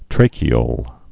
(trākē-ōl)